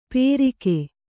♪ pērike